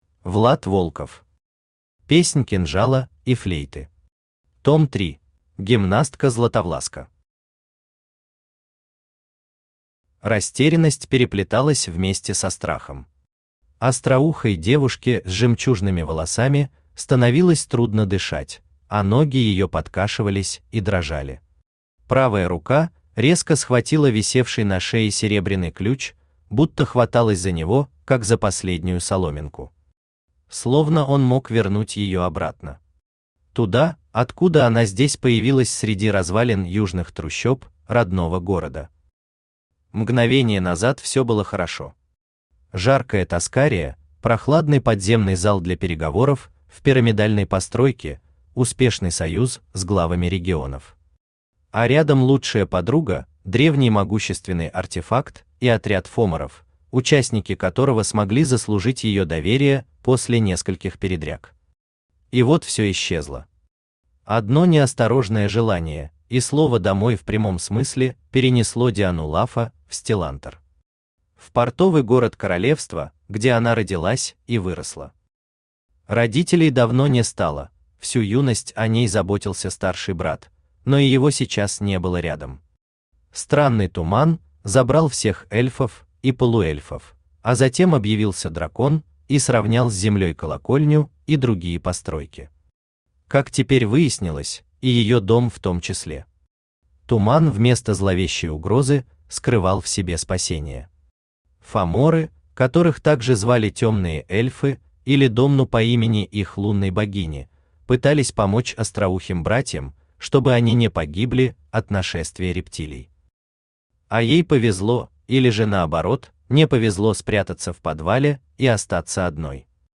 Аудиокнига Песнь кинжала и флейты. Том 3 | Библиотека аудиокниг
Aудиокнига Песнь кинжала и флейты. Том 3 Автор Влад Волков Читает аудиокнигу Авточтец ЛитРес.